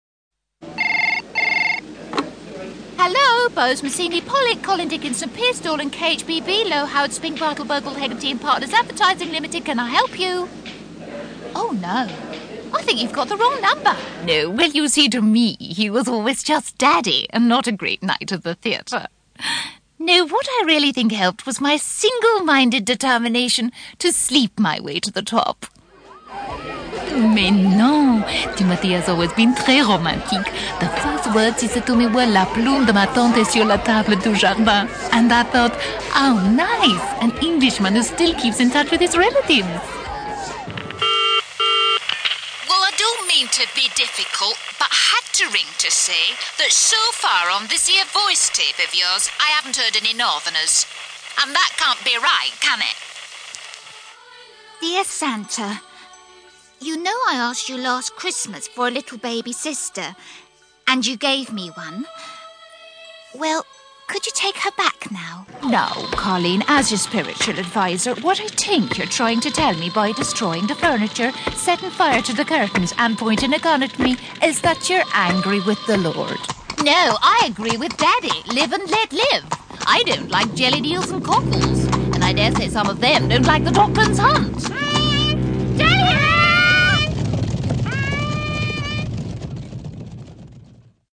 Soothing, gentle and informative.